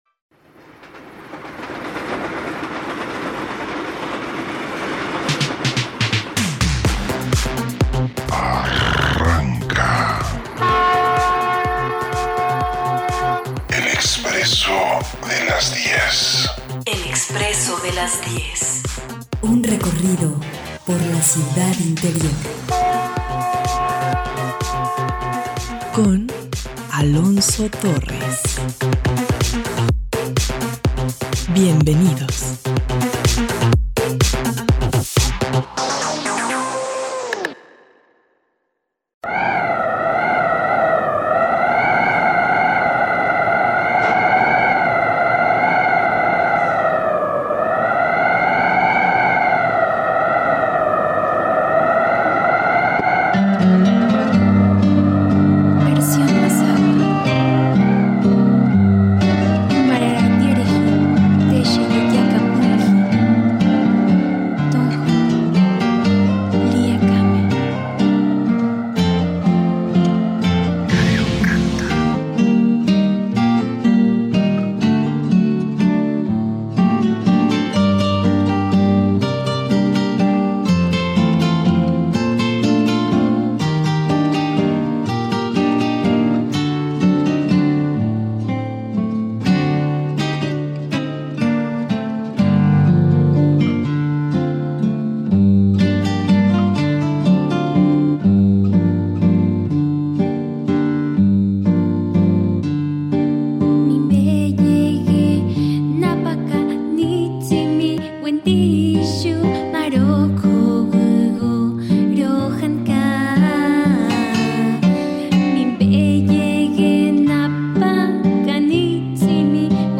En este podcast de El Expresso de las 10 escuchamos a mujeres Indígenas que comparten con todos nosotros sus saberes y sabores de la cocina tradicional mexicana, y conoceremos un proyecto de la Universidad de Guadalajara para el cuidado de la salud y la recuperación de la Gastronomía tradicional.